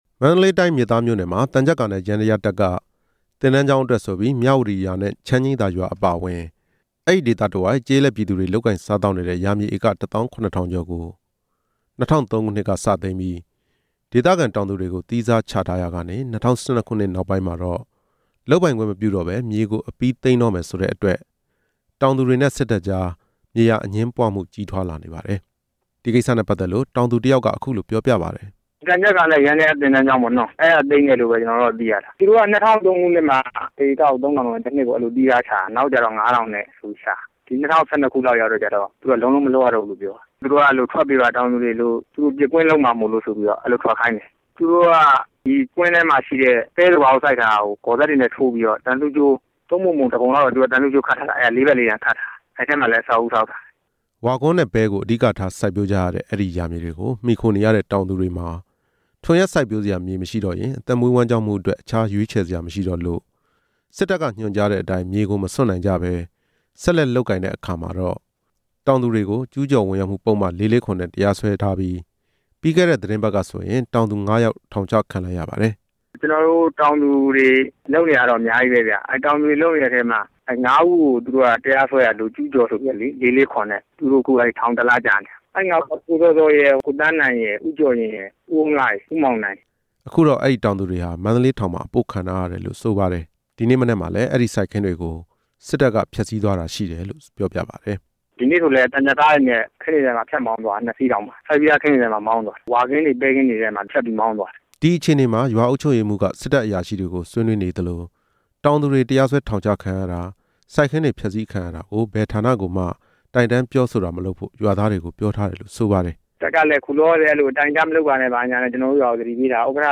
ဒီဖြစ်ရပ်နဲ့ပတ်သက်လို့ မျက်မြင်ရွာသားတစ်ဦးက RFA ကို အခုလိုပြောပါတယ်။